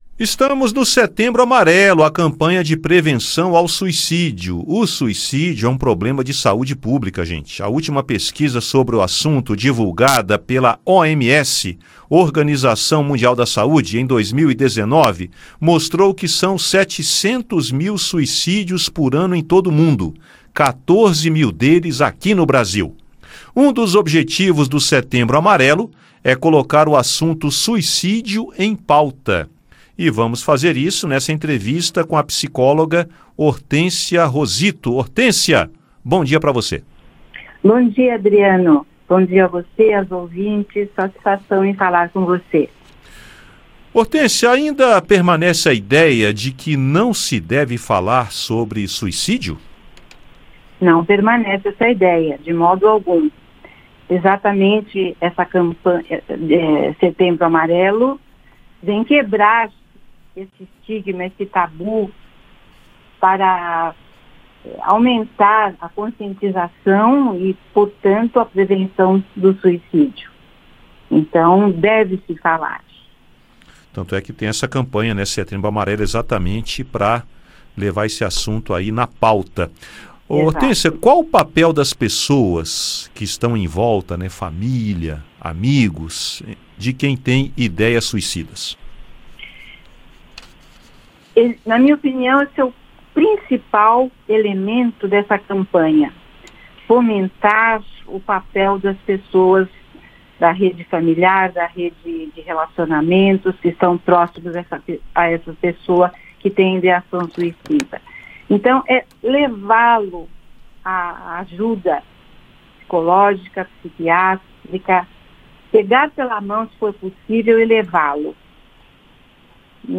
Psicóloga destaca importância de falar sobre o suicídio